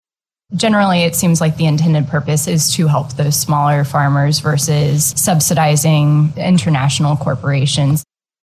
During the afternoon committee hearing, panel member Sen. Lauren Arthur (D-Kansas City) spoke on the specific purpose of HB 3: